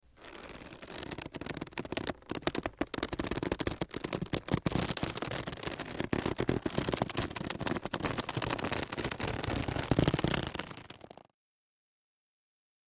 Rubber Stretching Out, X2